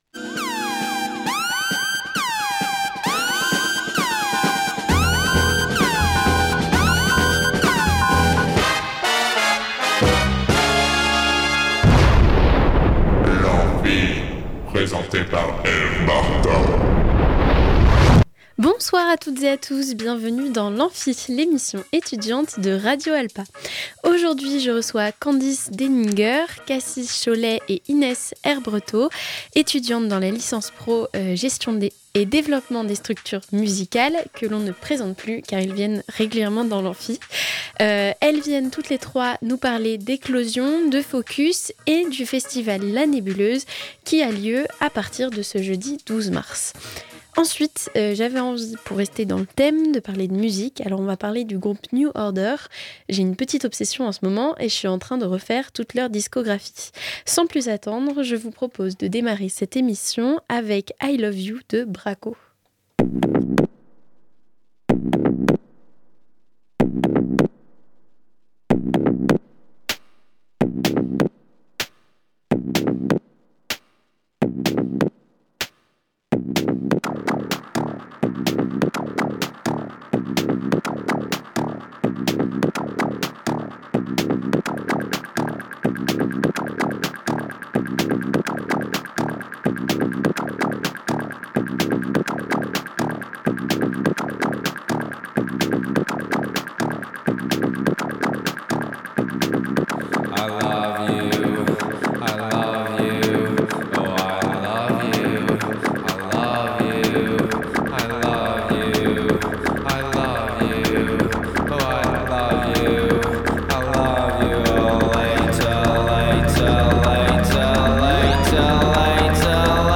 Elles sont toutes les trois étudiantes en licence pro Gestion et Développement des Structures Musicales.